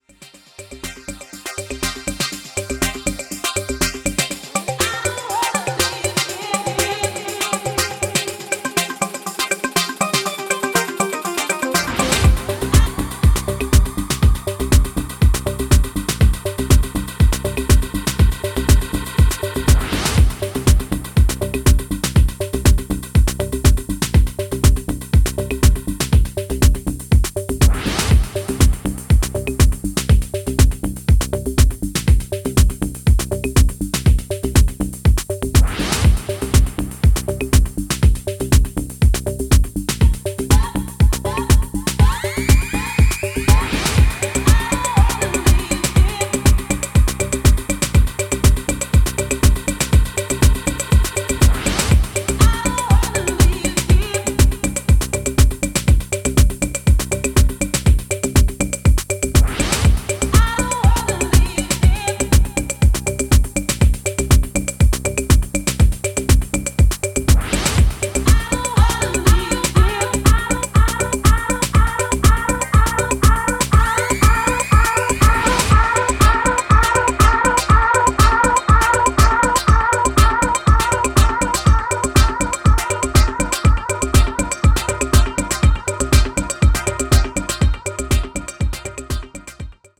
全体的に低重心のグルーヴ感もナイスで、Nu Disco方面の音好きもぜひチェックしてみてください。